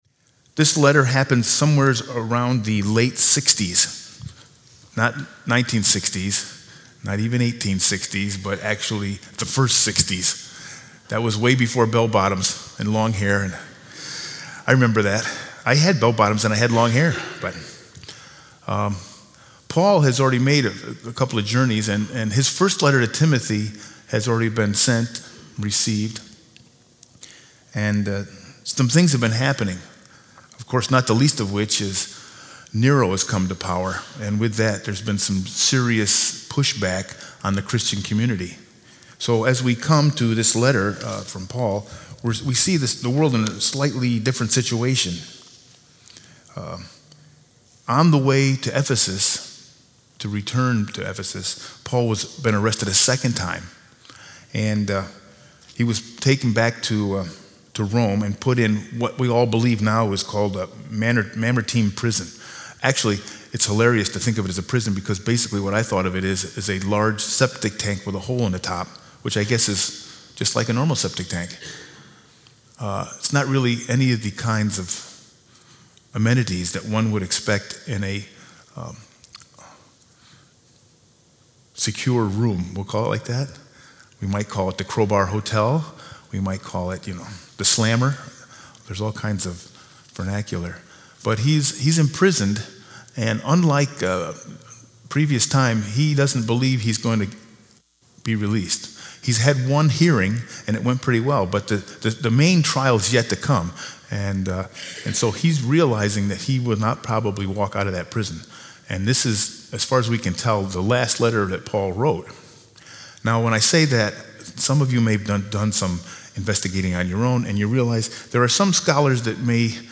Sermon 10-6-19: Growing Roots – CUMC 2018